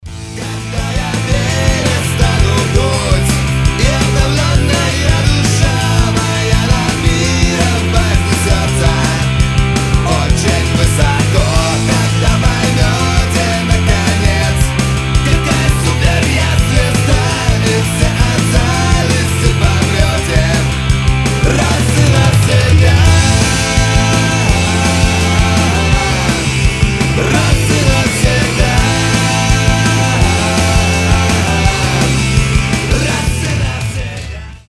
панк-рок